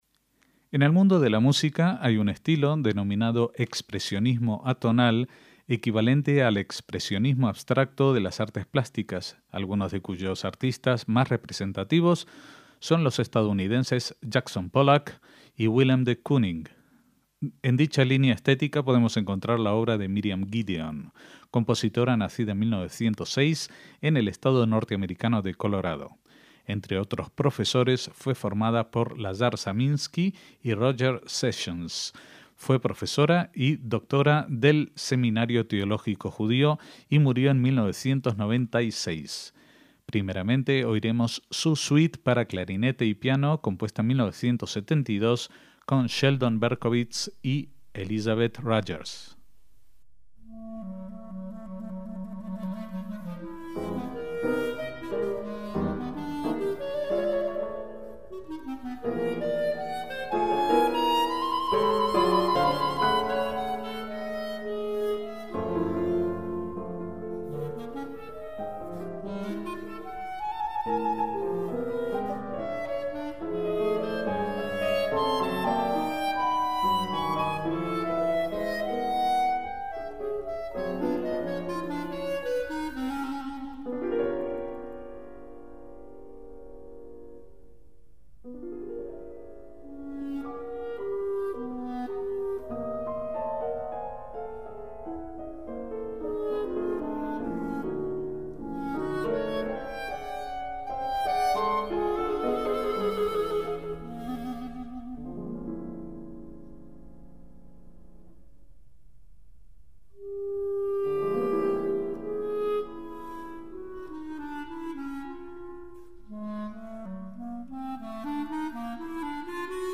MÚSICA CLÁSICA
para flauta y piano